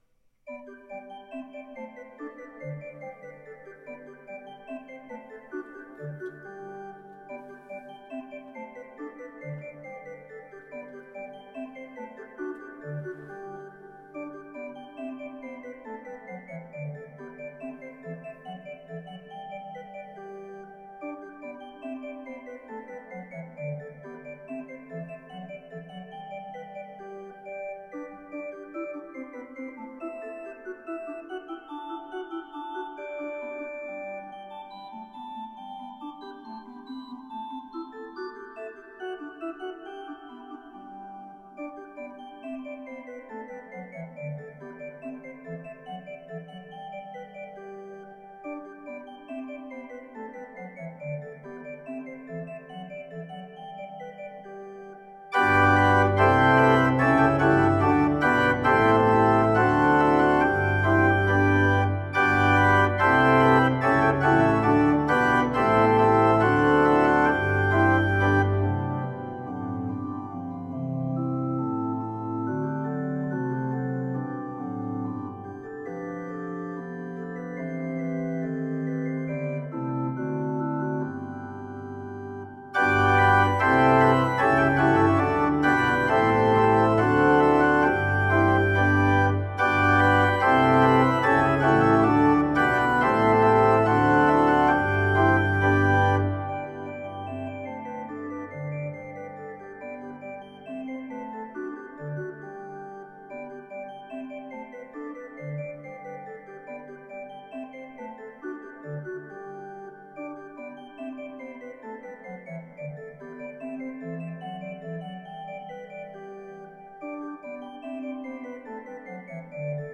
I wrote this arrangement just for fun one day.  It’s simple, but hopefully it’s bright, light, and inspiring.